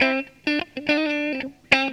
GTR 7  AM.wav